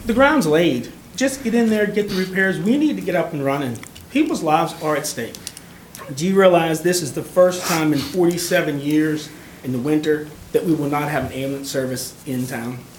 Lonaconing Mayor Jack Coburn urged the commissioners to pull out the stops and move forward quickly…